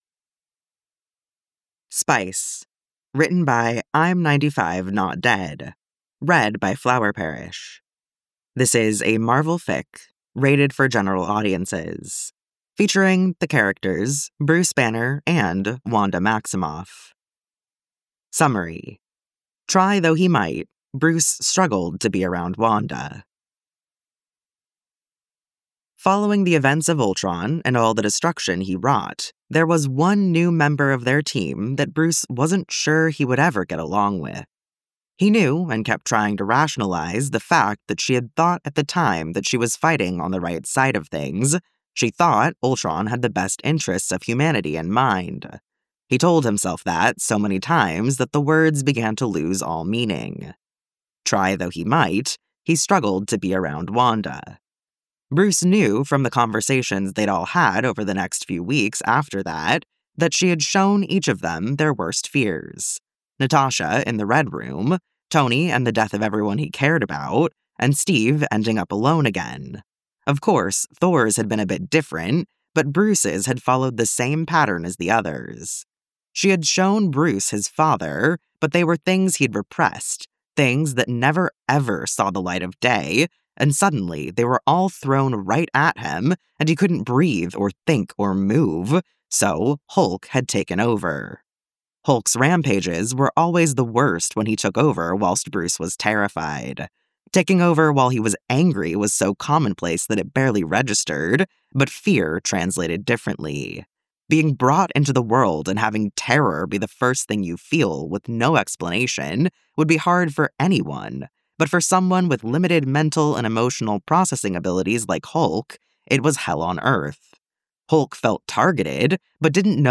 [Podfic]